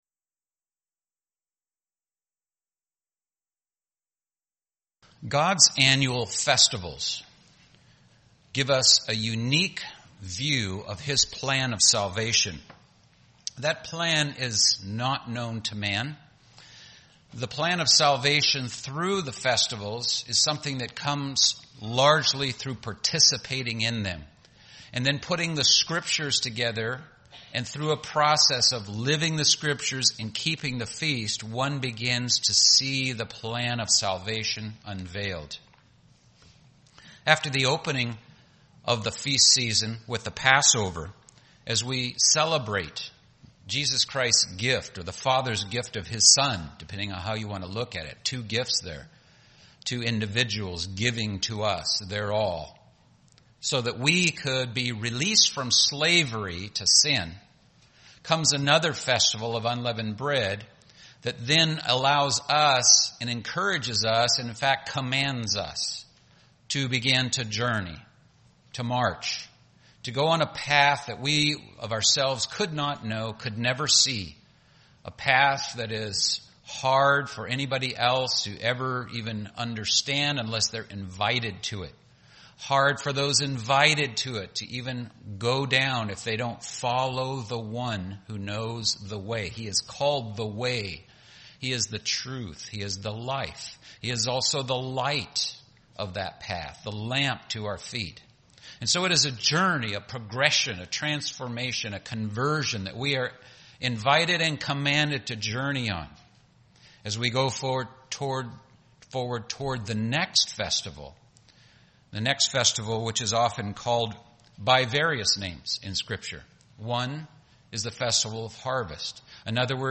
Those who rise to the challenge, face the risks, persevere and overcome the opposition are genuinely developing a nature like God's. UCG Sermon Transcript This transcript was generated by AI and may contain errors.